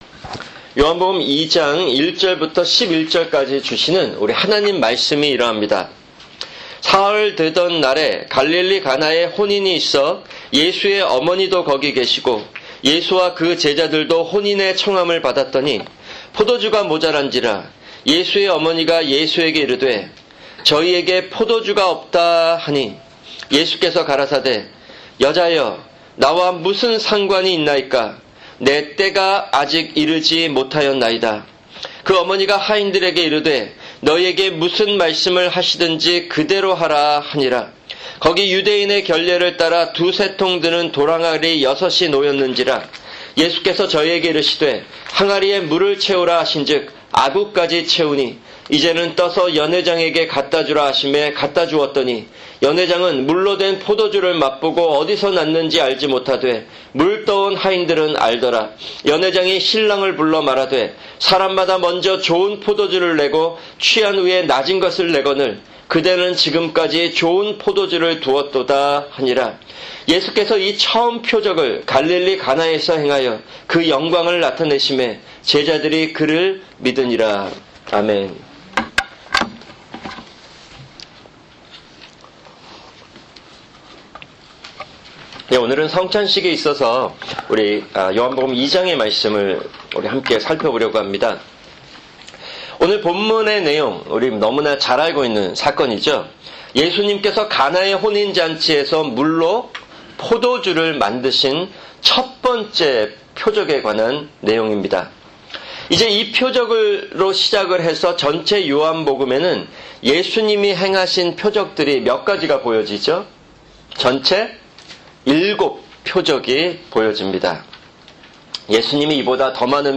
[주일 설교] 요한복음 2:1-11